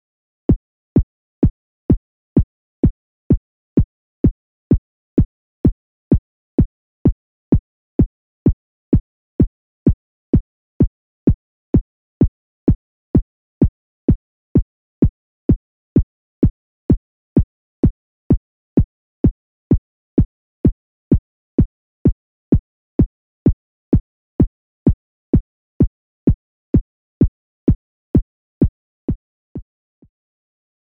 ・キック
今回は安定したキックが欲しかったので、ベーシックなサイン波から作る事にしました。VCOから好みの音程を探って、VCAのディケイで音の切れ方を微調整して仕上げました。
1_Kick.mp3